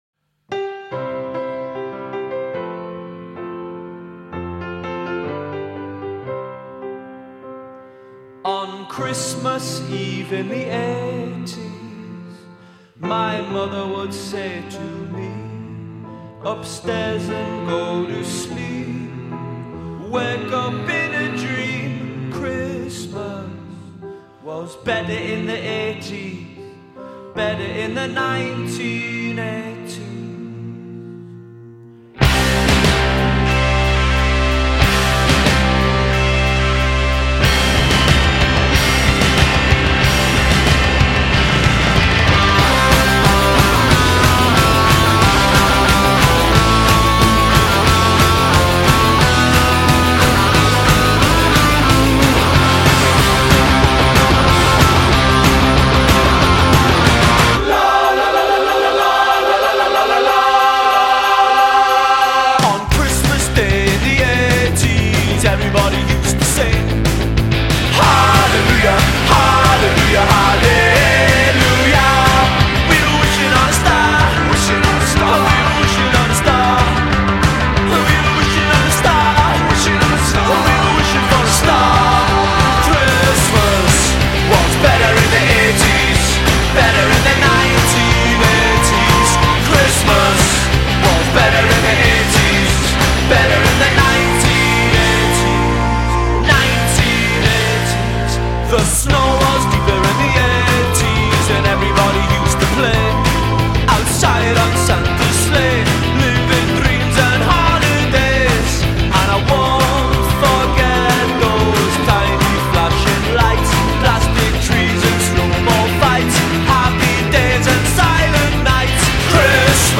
divertente e disimpegnata